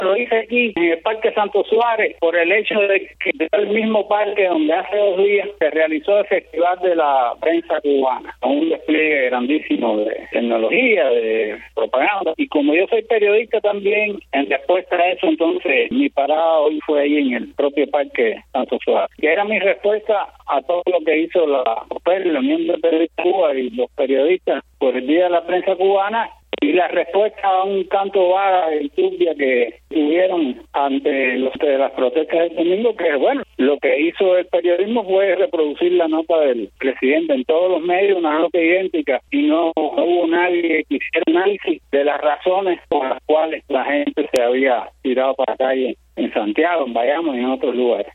habla para Martí Noticias